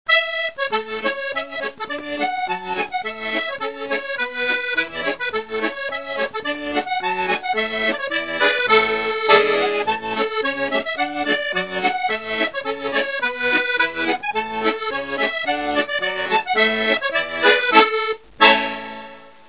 Acordió de piano